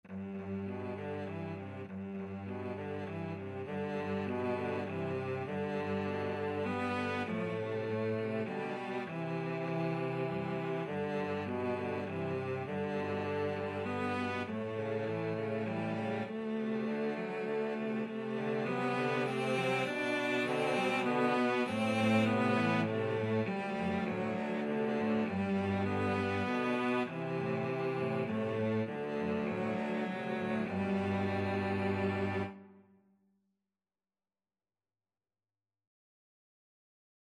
17th-century English folk song.
G major (Sounding Pitch) (View more G major Music for Cello Trio )
Moderato
Cello Trio  (View more Easy Cello Trio Music)